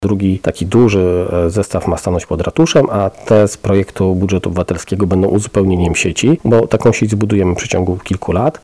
– Automaty które wygrały w budżecie obywatelskim będą uzupełnieniem sieci recyklingowej, która powstaje na Bielanach – tłumaczy burmistrz.
burmistrz2-2.mp3